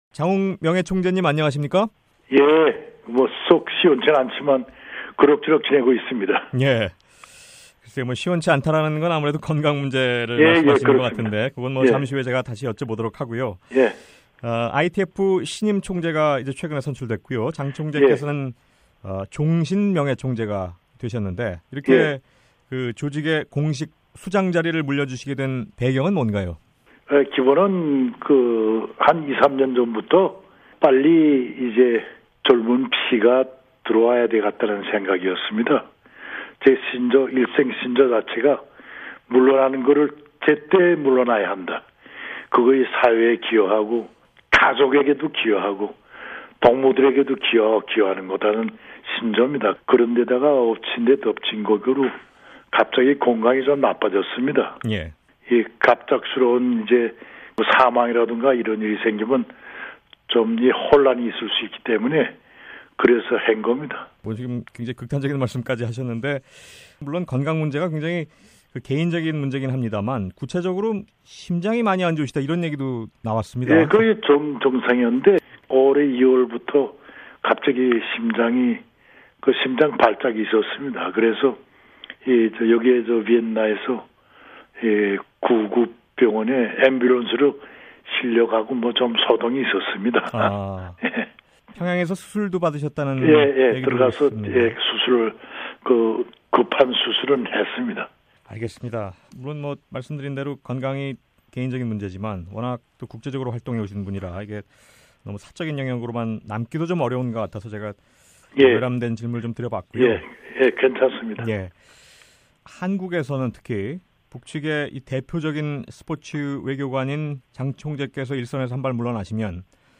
[단독 인터뷰] 장웅 북한 IOC 위원